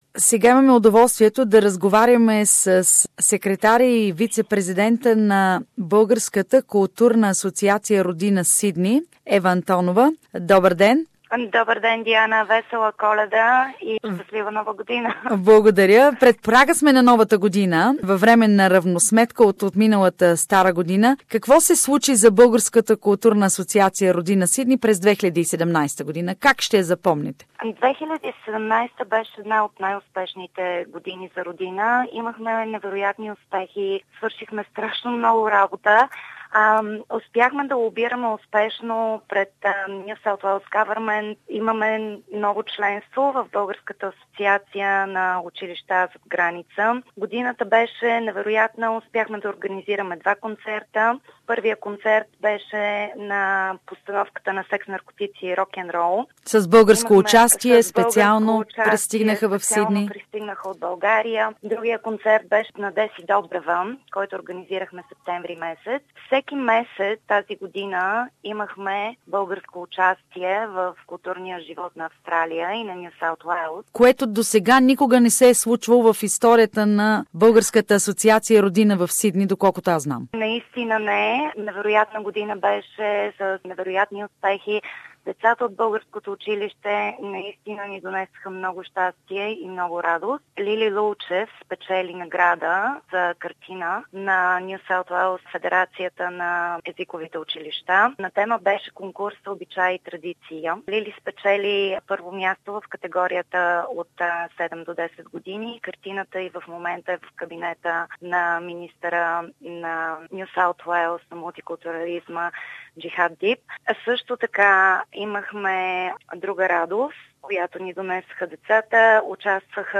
Всеки месец от 2017-та година българската общност в Сидни участва в културния живот на щата Нов Южен Уелс. Интервю